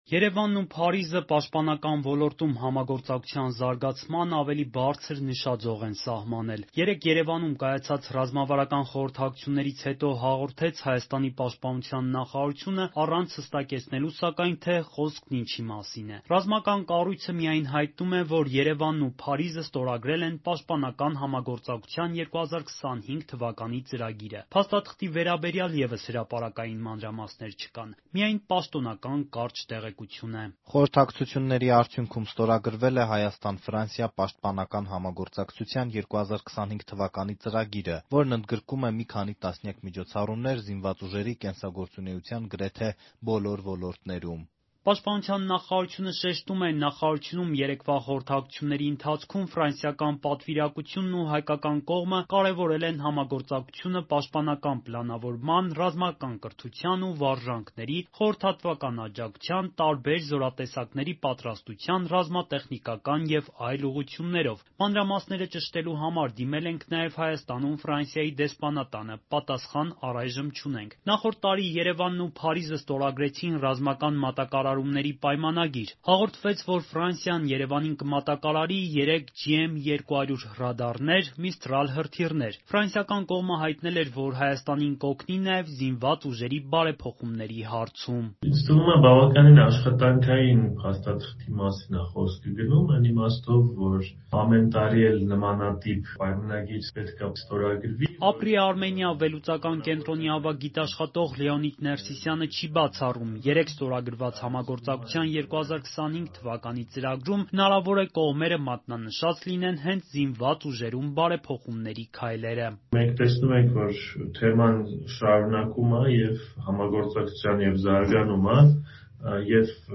Ամբողջական տարբերակը՝ «Ազատության» ռադիոռեպորտաժում.